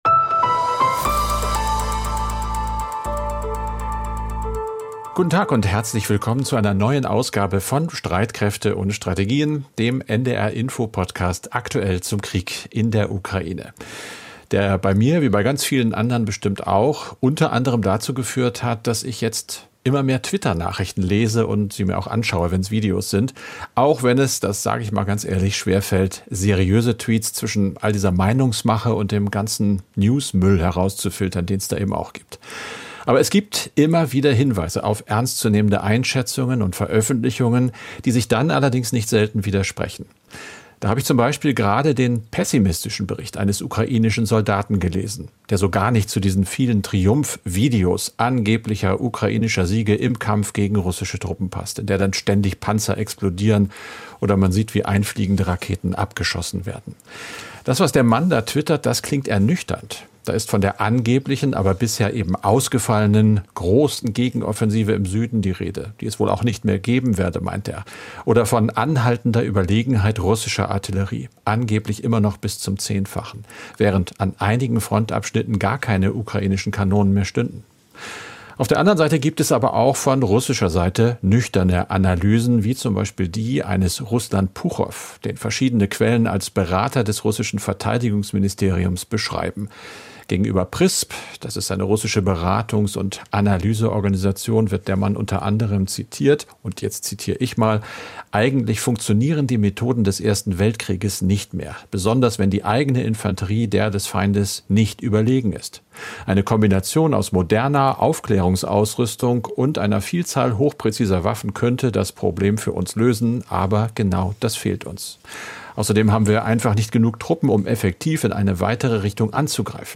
Nachrichten - 16.08.2022